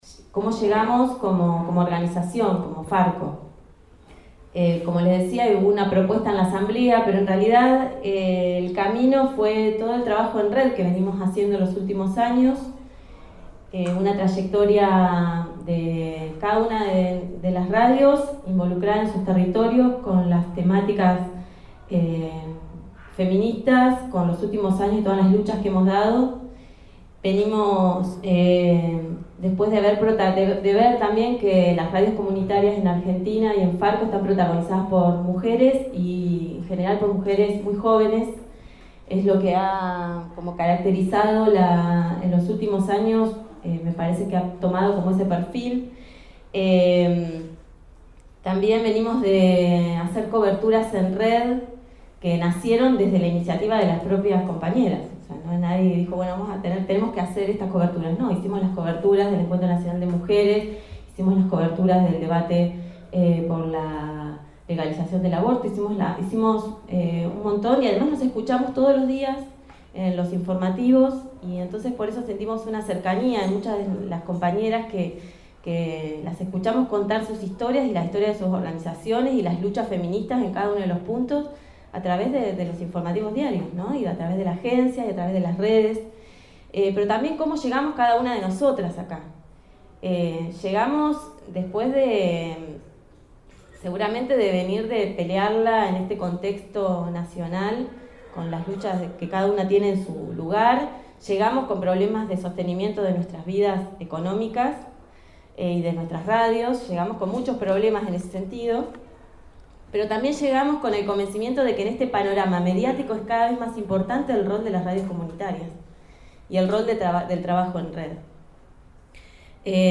en la apertura del Encuentro